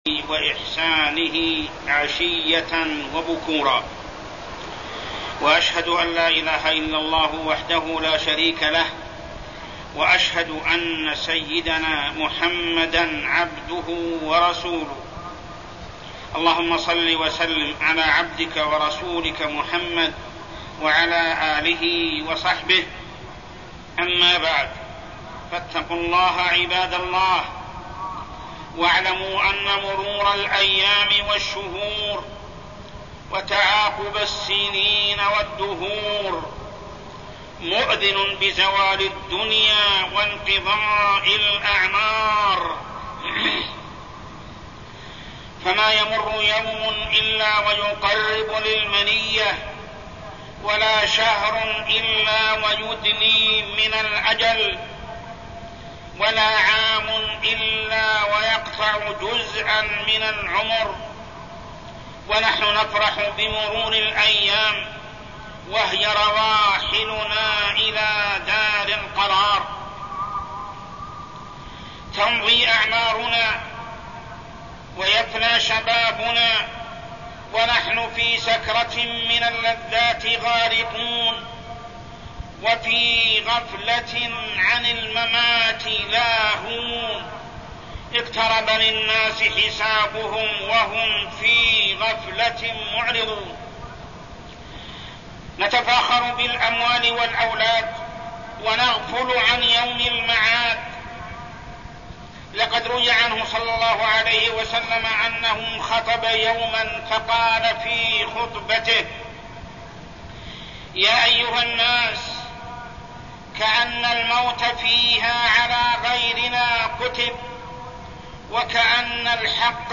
تاريخ النشر ٢٣ ذو الحجة ١٤١١ هـ المكان: المسجد الحرام الشيخ: محمد بن عبد الله السبيل محمد بن عبد الله السبيل العام الهجري الجديد